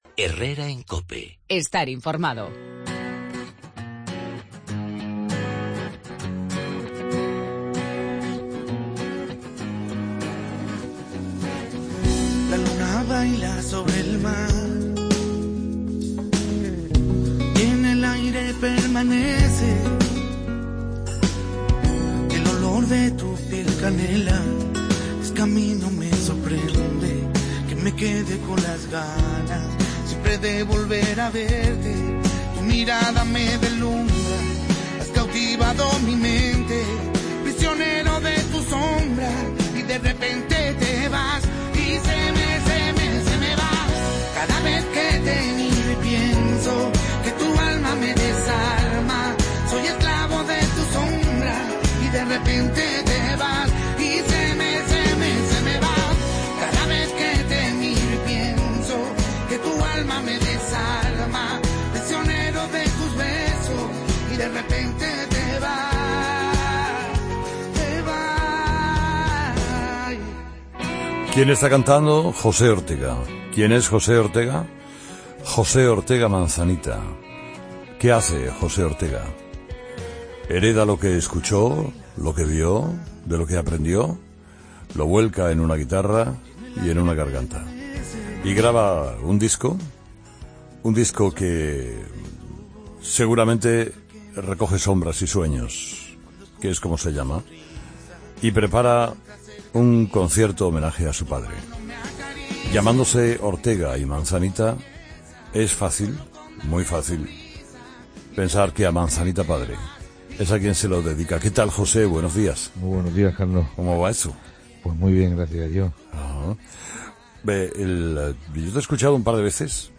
AUDIO: Escucha la entrevista a José Ortega, 'Manzanita', en 'Herrera en COPE'